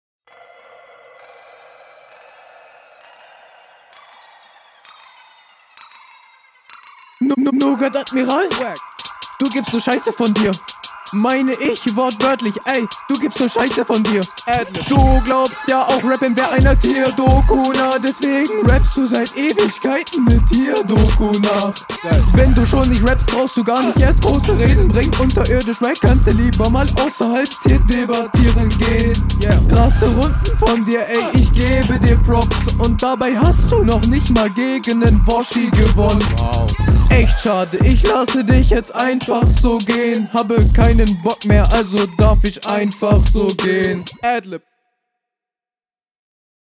Voicecrack Flow xD